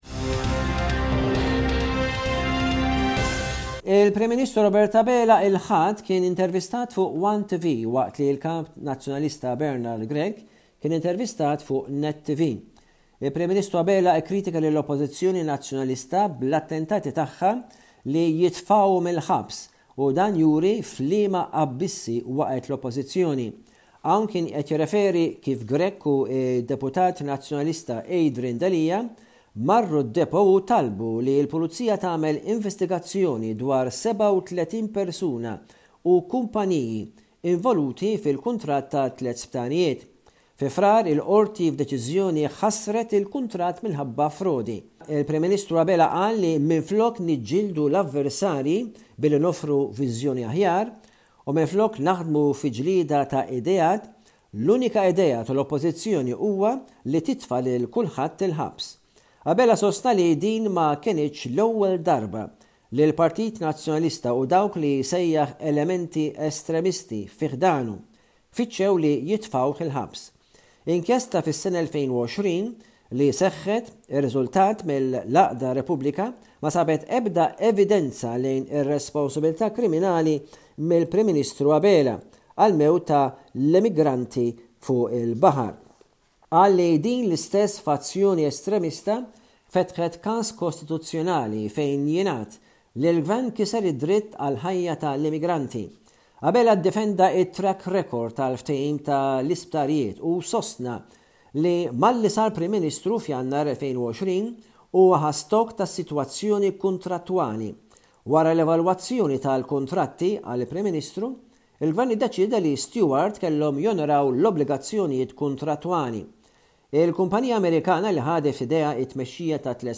News report from Malta by SBS Radio correspondent